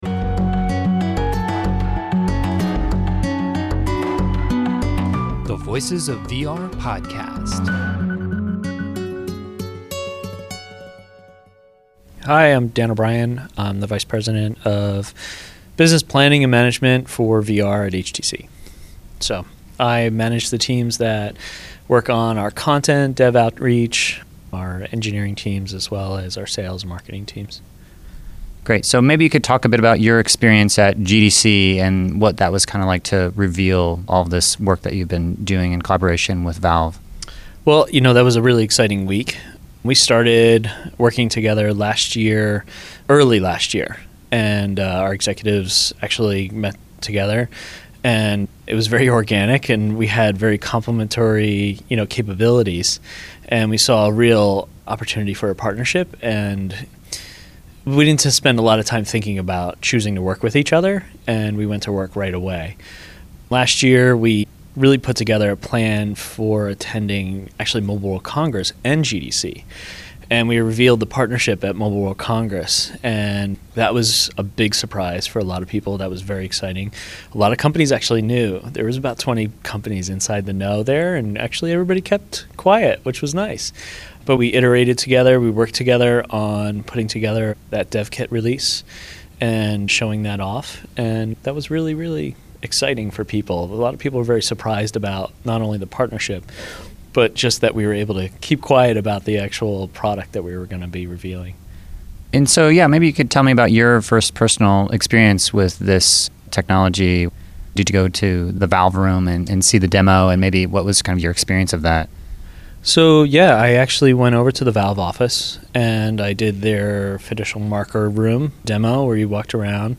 I had a chance to catch up with him at PAX Prime in the HTV Vive private demo area.